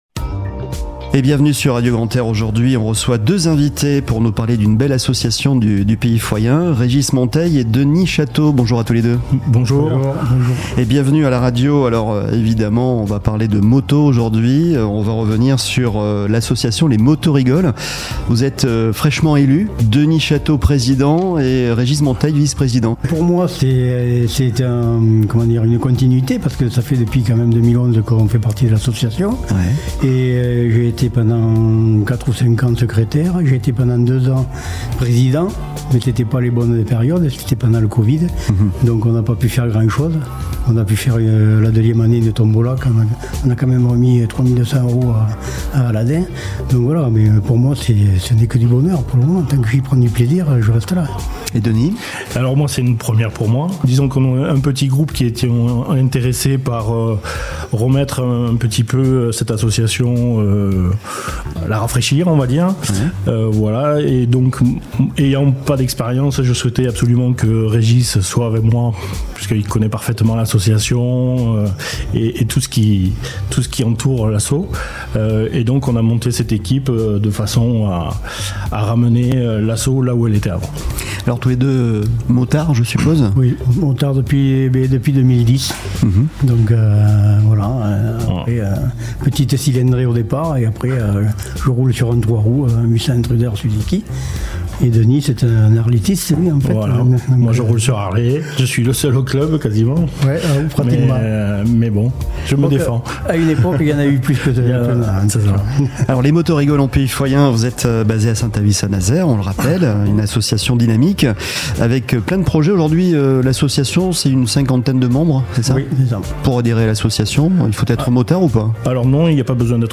Le podcast des invités de Radio Grand "R" !